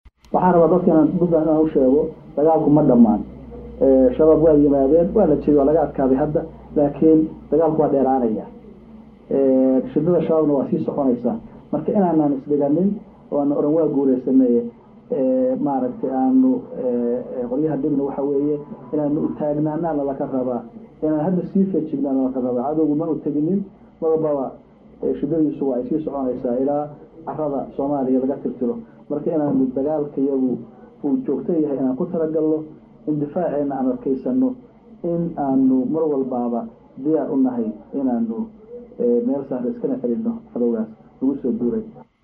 Madaxweynaha Puntland oo saxaafada la hadlayay ayaa sheegay in la jabiyay argagixisada shabaab oo maydkooda la soo bandhigey shalay oo uu gaarayay lixdan u badan badan dad ayna waalidkoodu warka ahayn oo la soo afduubey siyaabo kala duwana wax loo sheegay caruurtu ha u badnaatee.